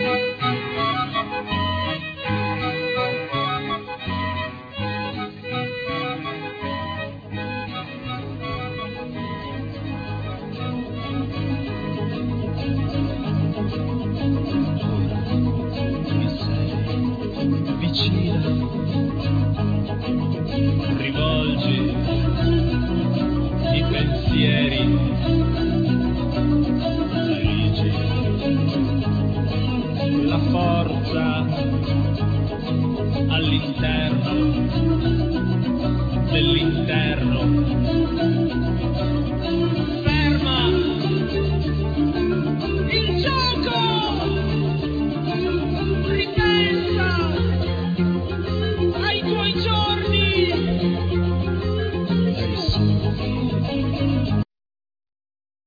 -choir-
Acoustic+12strings+Electoric Guitar,Harp
Drmus,Percussions
Grando piano,Hammond organ,Synth
Cello
Lead Vocals
Violin
Clarinets